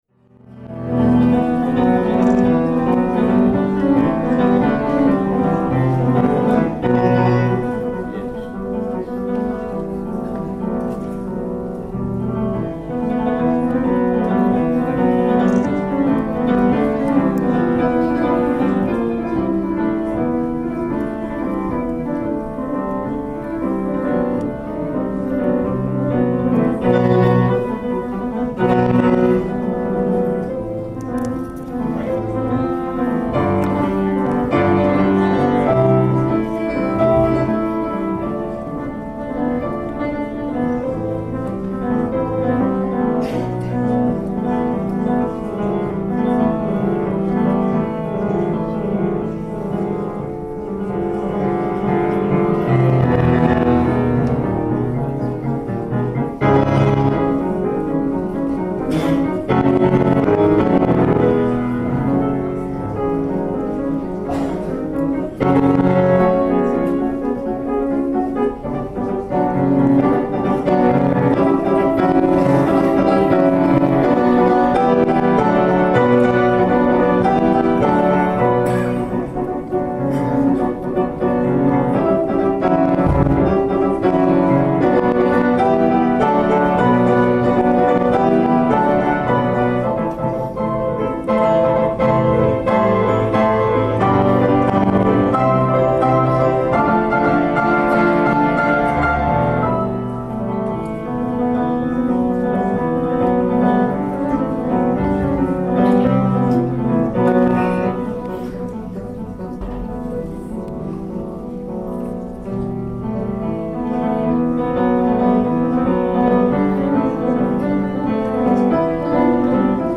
22-nji martda Moskwada Nowruz baýramy mynasybetli türkmenleriň baýramçylyk dabarasy geçirildi.